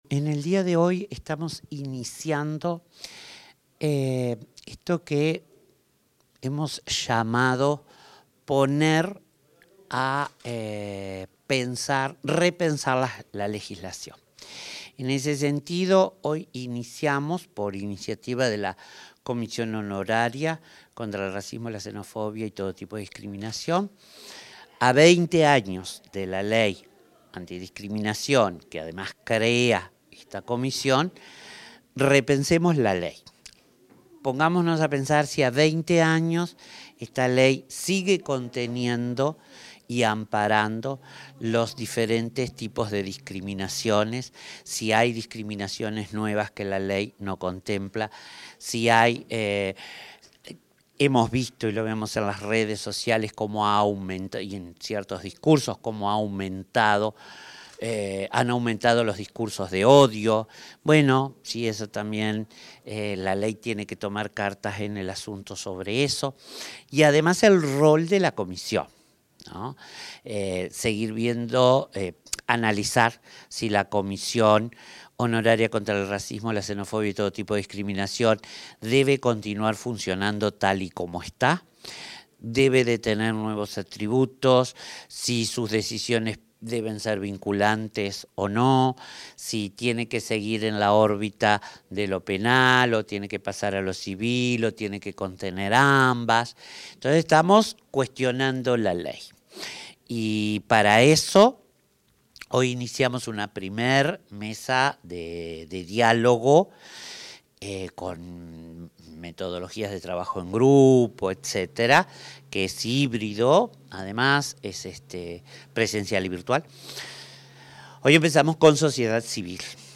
Declaraciones de la secretaria de Derechos Humanos de Presidencia, Collette Spinetti